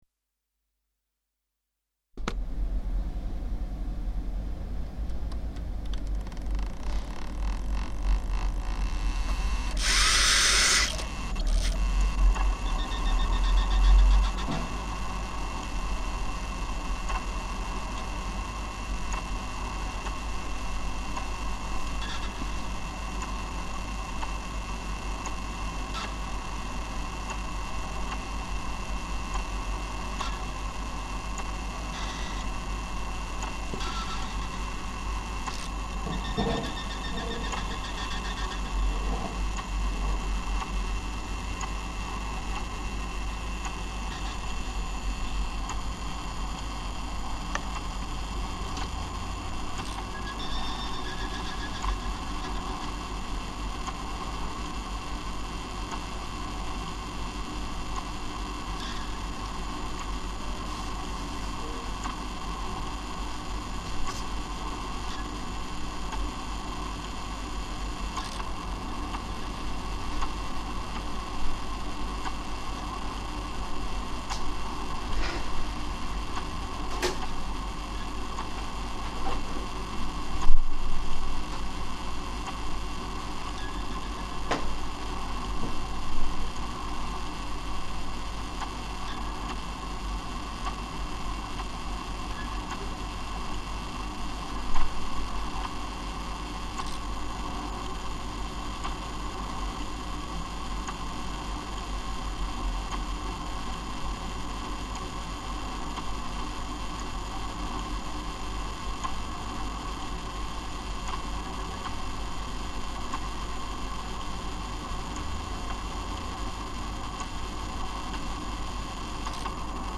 MZ-R70 mit Mikrophonen OKMII auf Minidisk.